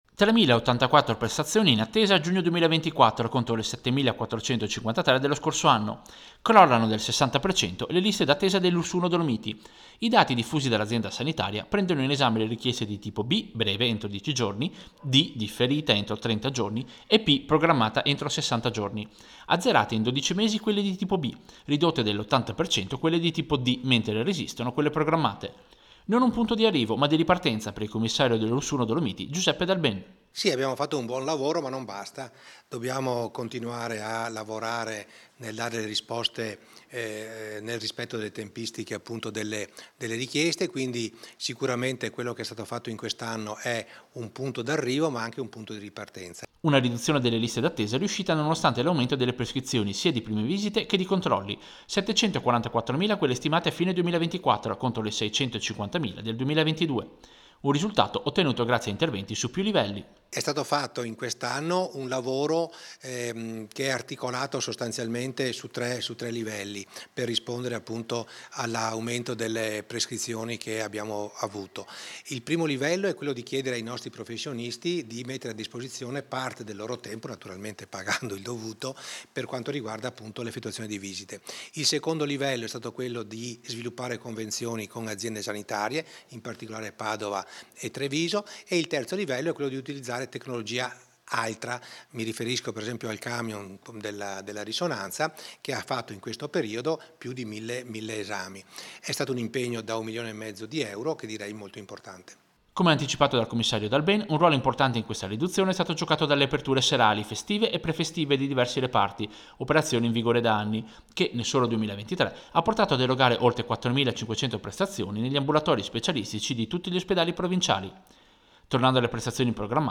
Servizio-Lista-dattesa-Ulss.mp3